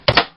描述：北美原装NES硒鼓插槽门的声音关闭
Tag: 任天堂NES 视频游戏